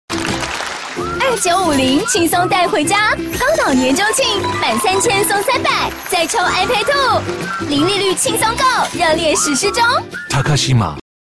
國語配音 女性配音員